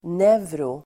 Ladda ner uttalet
Uttal: [²n'ev:ro-]